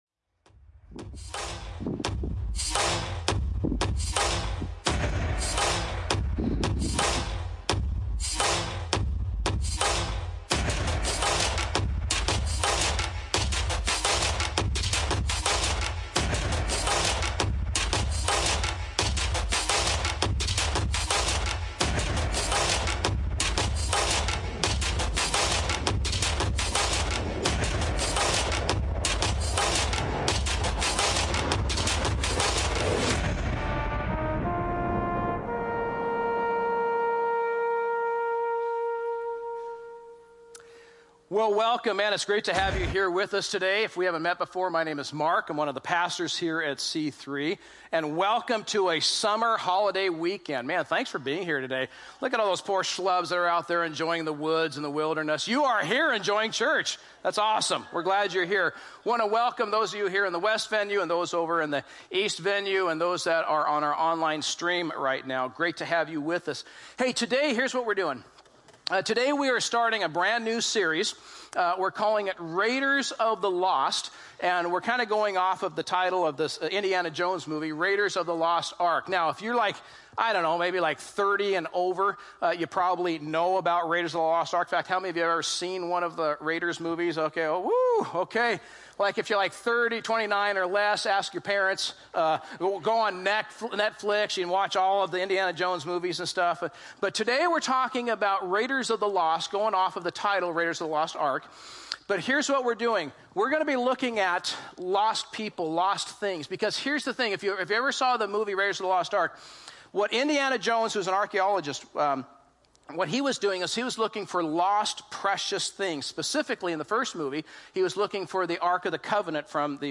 A message from the series "Raiders of the Lost."